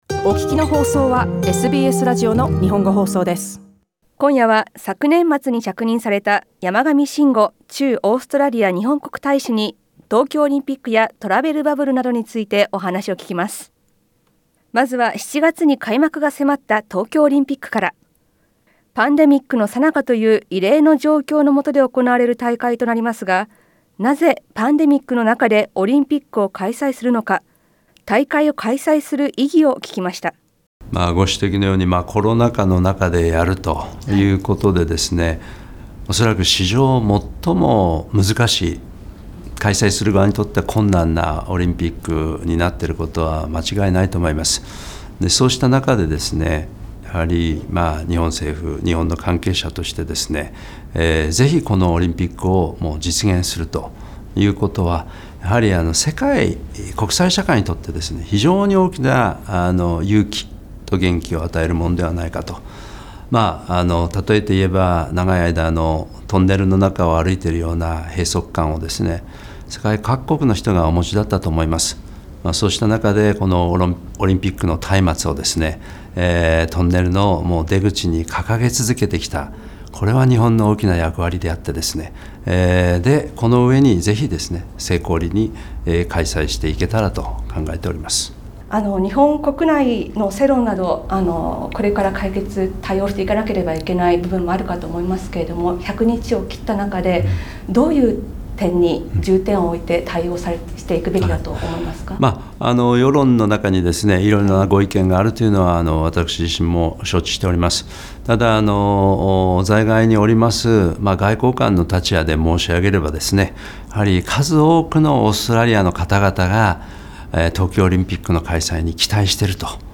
シドニーにあるSBSを訪れ、SBS World Newsと SBS Japaneseの取材を受けました。 インタビューでは、東京オリンピックや、日本とオーストラリアとのトラベルバブルの可能性、日豪の深いつながり、そして日系コミュニティーへのメッセージを含めてお話しを聞きました。